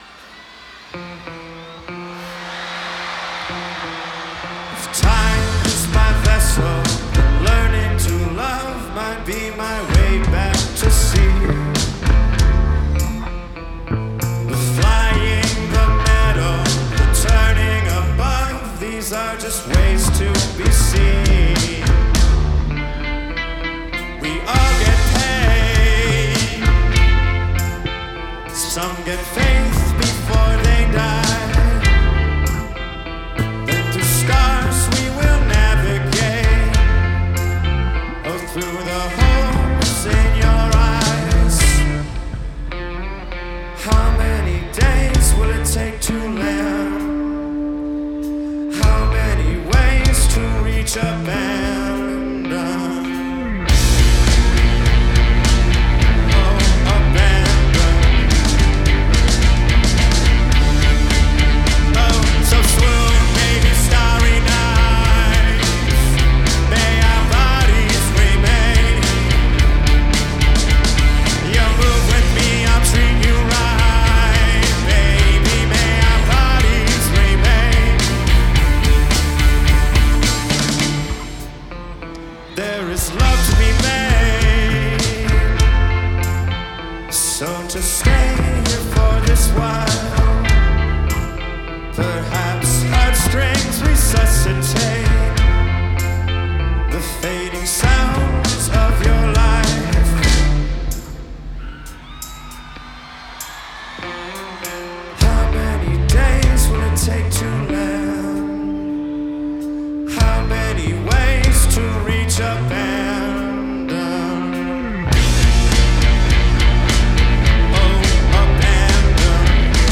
• Жанр: Альтернатива